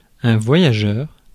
Ääntäminen
France: IPA: [vwa.ja.ʒœʁ]